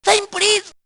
Unused voices